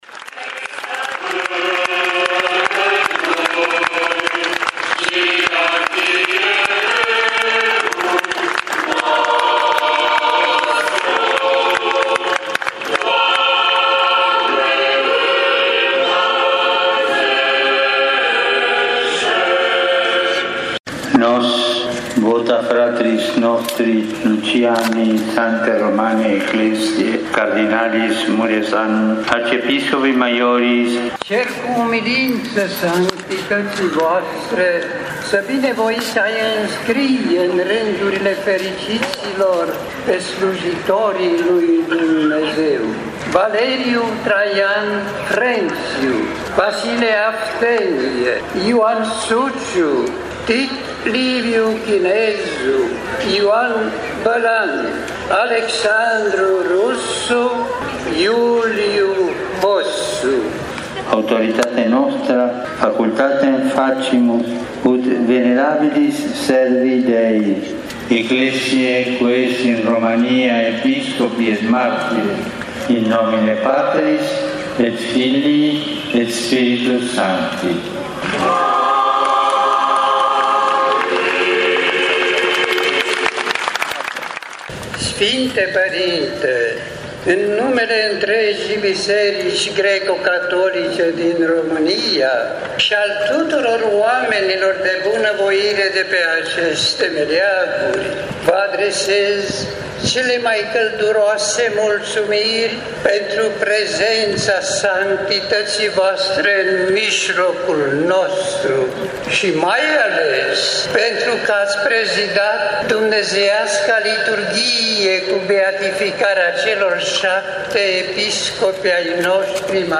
a participat pe Câmpia Libertății la acest moment și vă prezintă momentul beatificării: